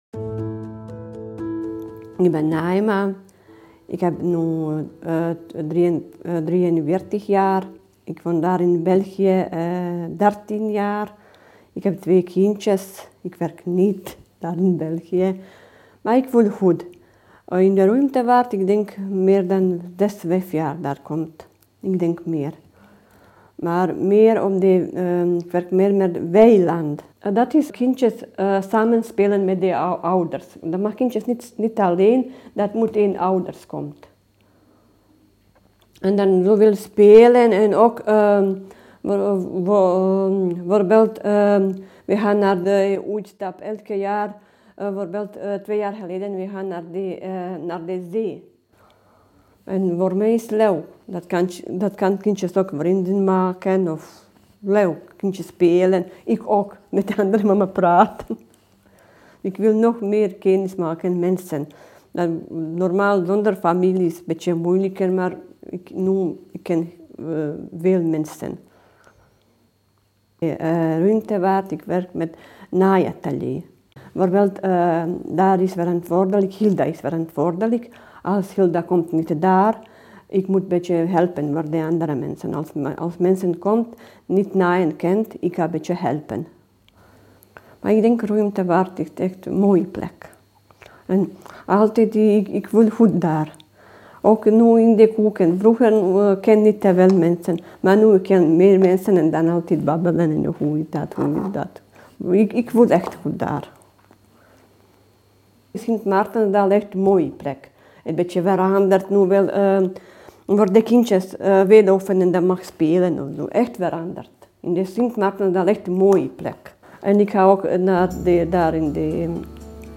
Elk van hen nam ons ook mee naar een plek in de buurt waar zij zich goed voelen. Daar maakten we telkens een audioverhaal.